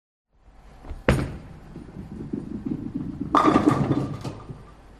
Free Horror sound effect: Chains Dragging.
Chains Dragging
432_chains_dragging.mp3